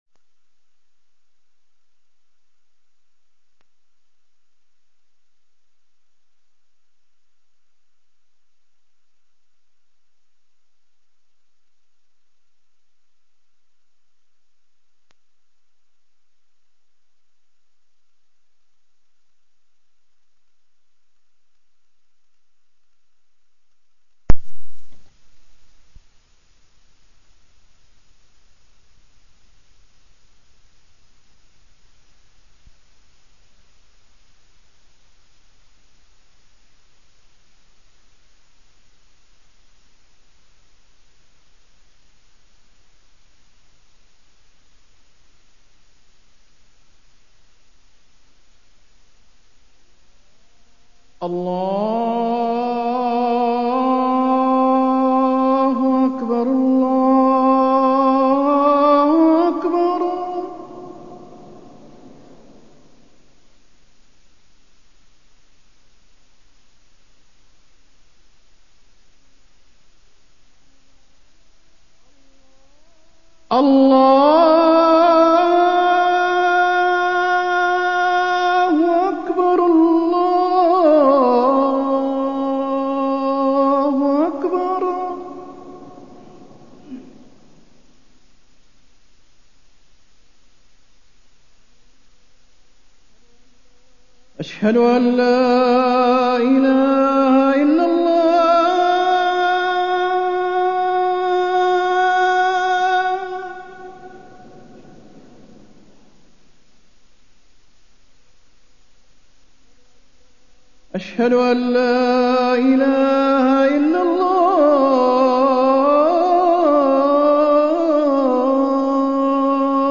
تاريخ النشر ٣٠ جمادى الأولى ١٤٢٣ هـ المكان: المسجد النبوي الشيخ: فضيلة الشيخ د. حسين بن عبدالعزيز آل الشيخ فضيلة الشيخ د. حسين بن عبدالعزيز آل الشيخ التمسك بالوحيين